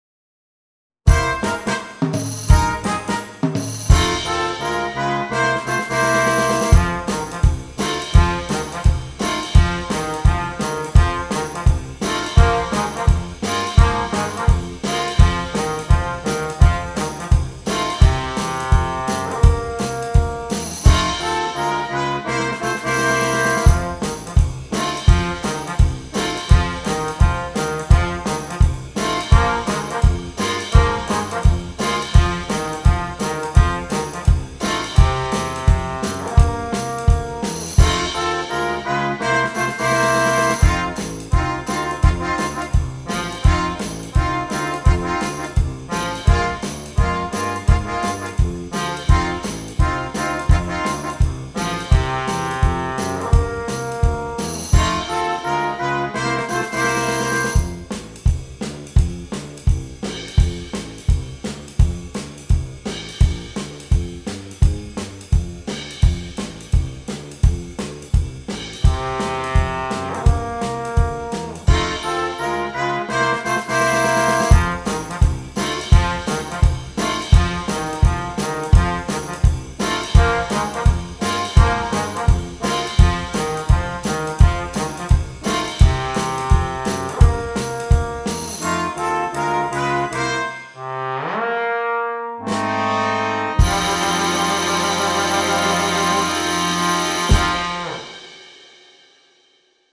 Gattung: Konzertstück für Jugendblasorchester
Besetzung: Blasorchester